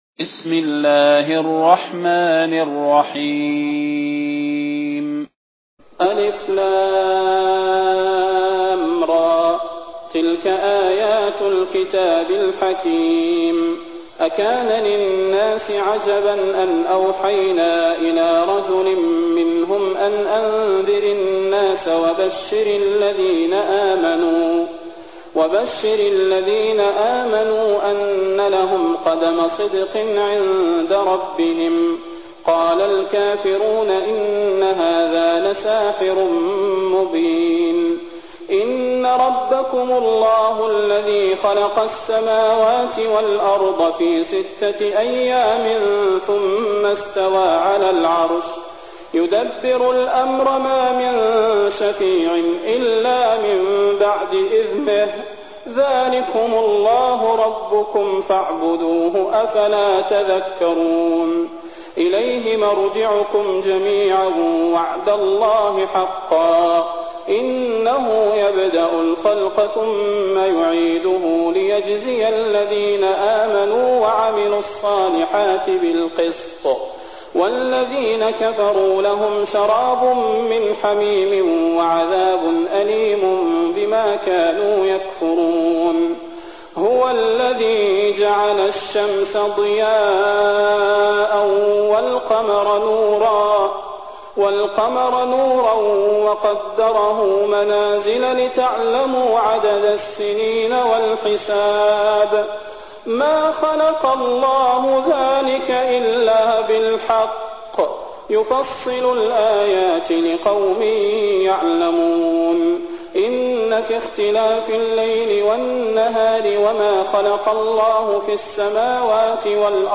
المكان: المسجد النبوي الشيخ: فضيلة الشيخ د. صلاح بن محمد البدير فضيلة الشيخ د. صلاح بن محمد البدير يونس